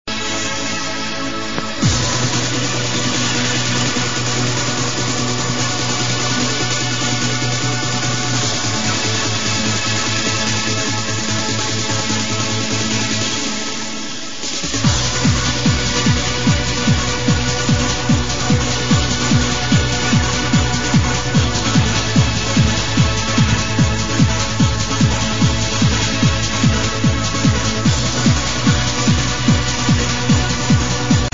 Confused trance track - uplifting - Help needed
sounds like stuff on energetic records or something